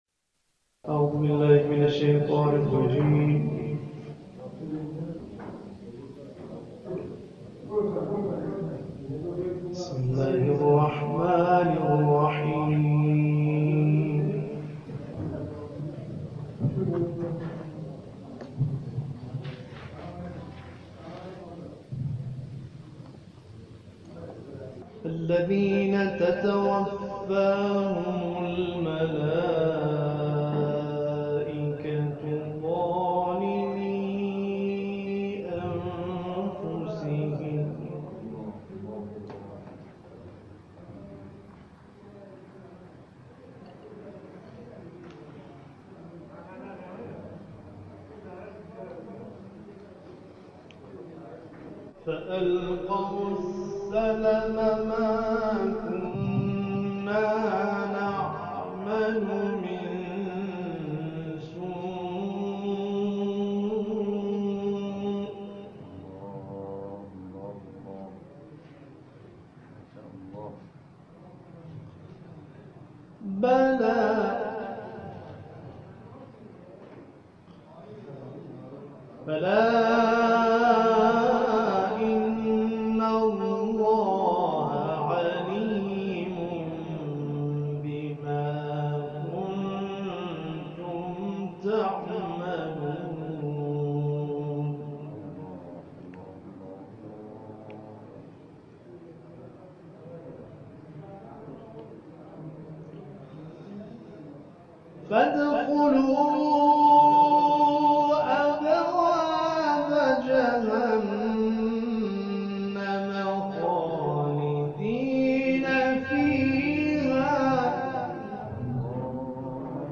این تلاوت هفته گذشته در طلیعه سوگواری ماه محرم در قزوین اجرا شده است.